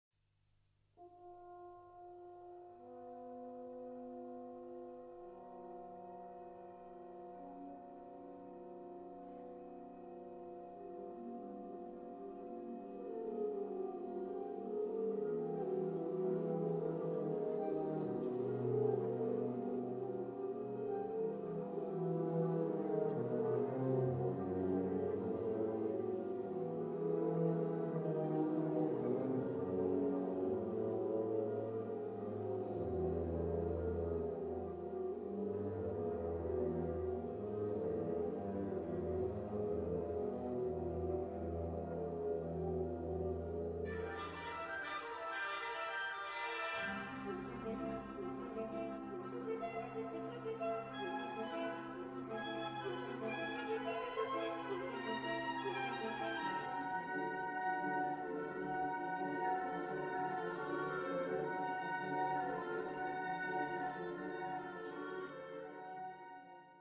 In Five Movements (performed without interruption):